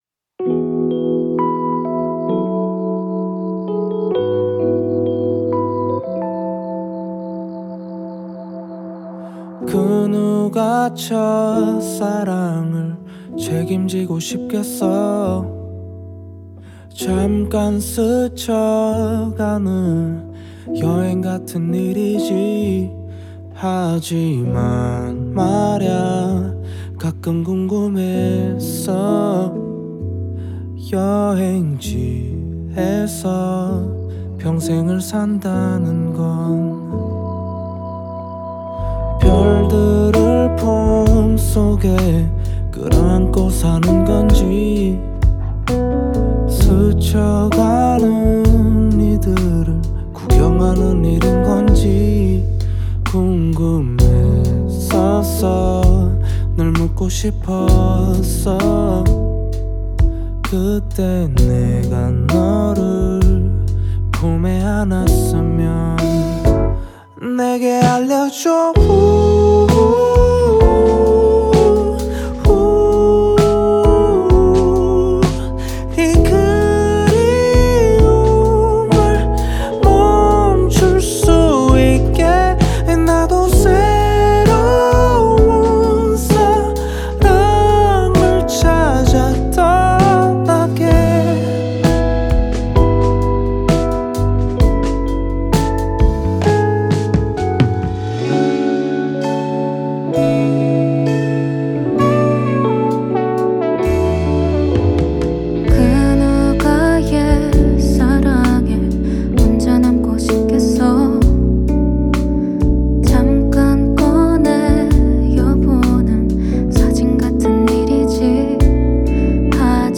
KPop Song
Label Ballad